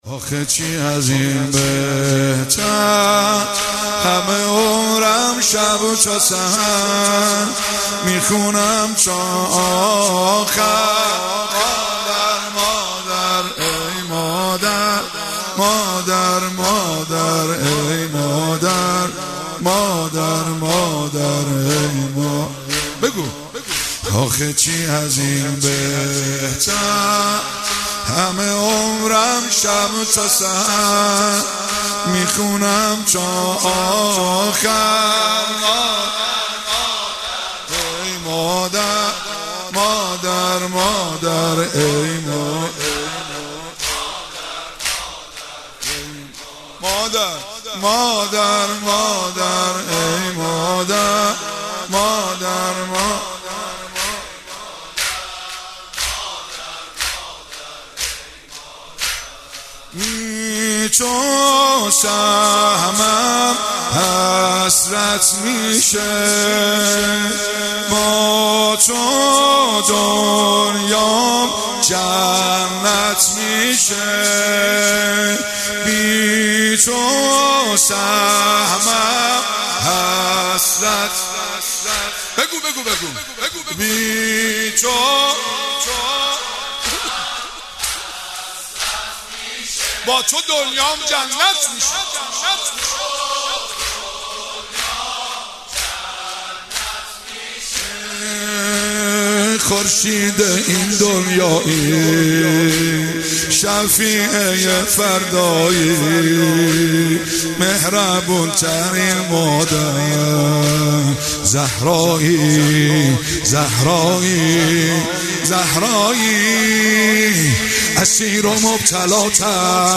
جلسه هفتگی هیئت فاطمیون قم (۱۰بهمن۹۷)